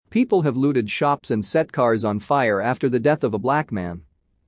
【ノーマル・スピード】